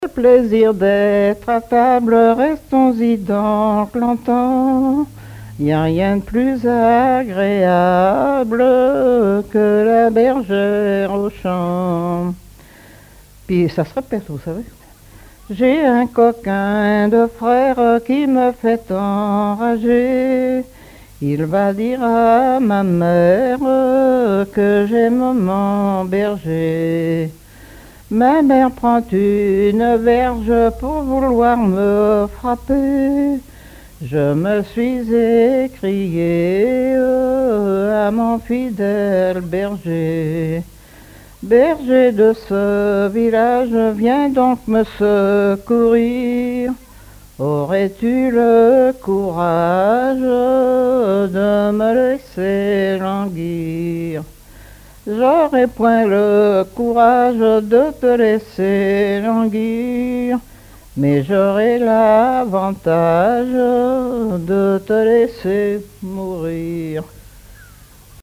Genre strophique
collecte en Vendée
Pièce musicale inédite